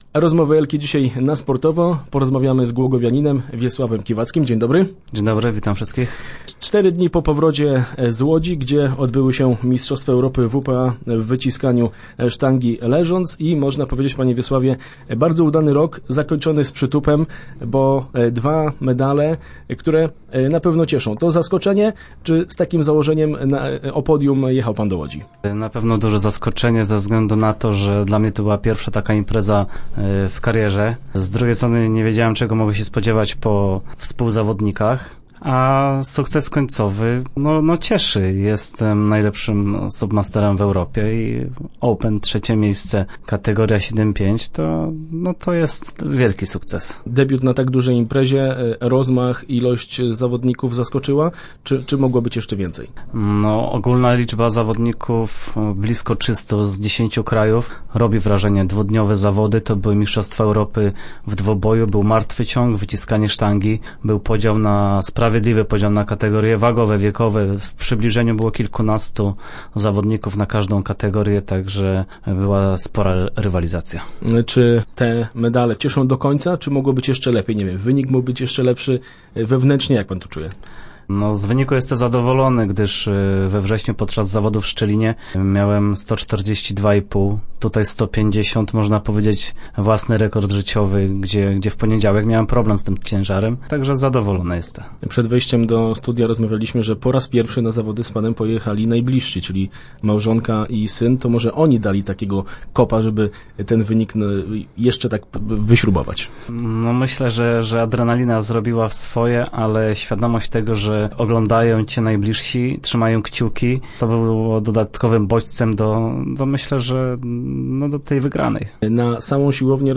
Start arrow Rozmowy Elki arrow Dwanaście startów i tyle samo razy na podium